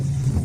conveyor.ogg